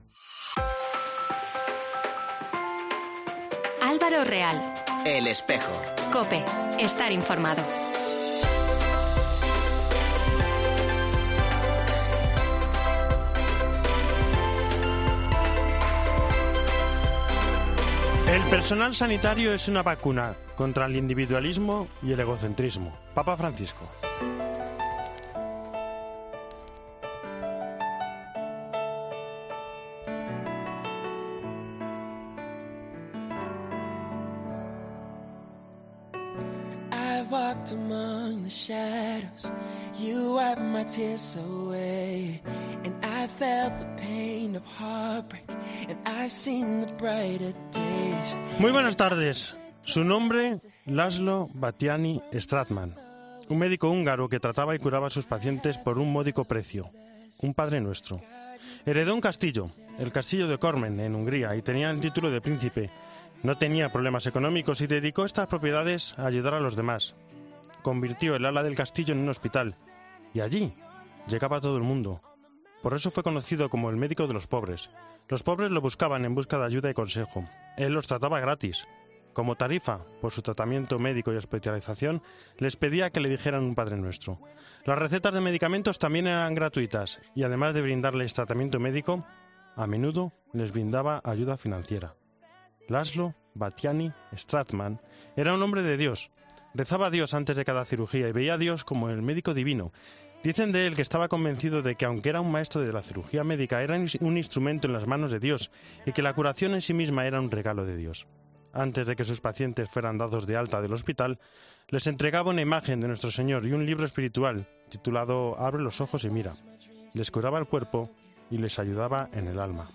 Ángelus, entrevista e Hispanoamérica.